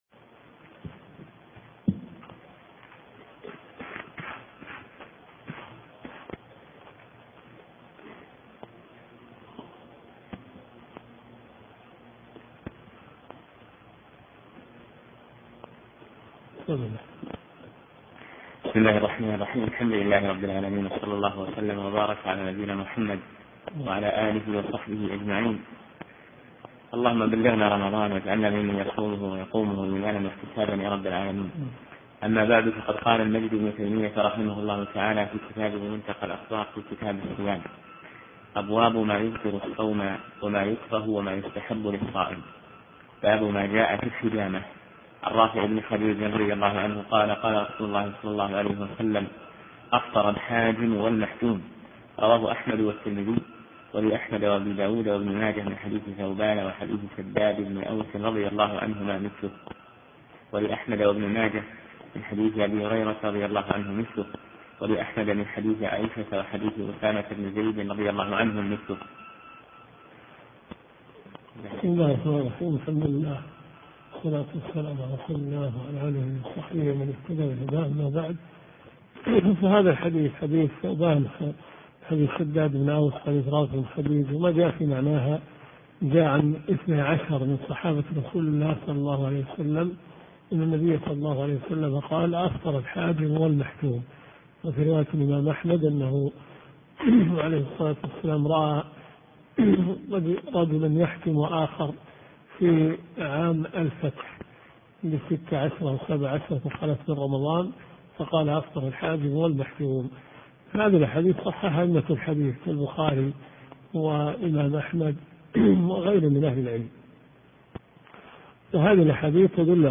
دورة صيفية في مسجد معاذ بن جبل .